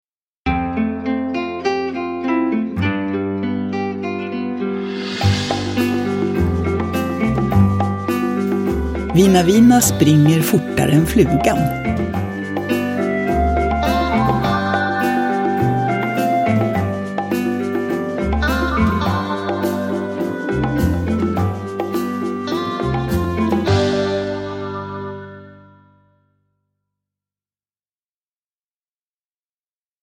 Vina Vina springer fortare än flugan – Ljudbok – Laddas ner